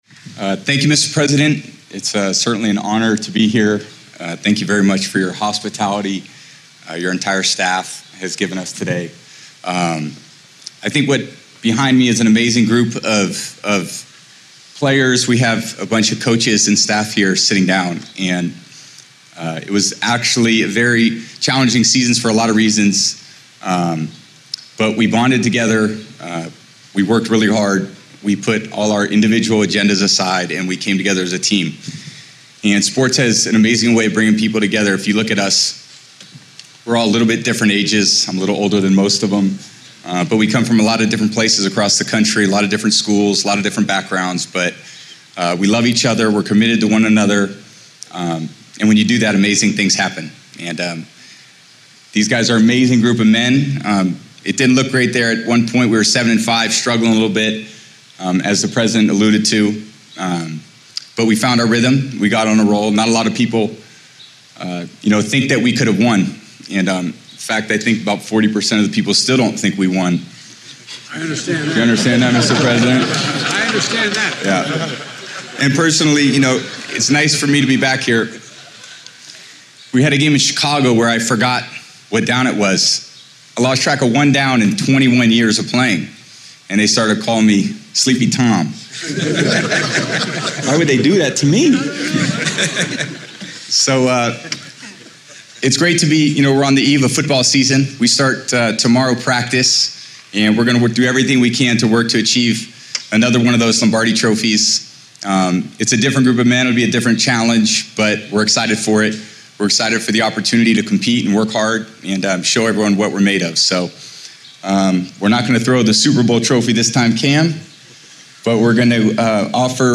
Address at a White House Event Honoring the 2021 Super Bowl Champion Tampa Bay Buccaneers
Audio Note: AR-XE = American Rhetoric Extreme Enhancement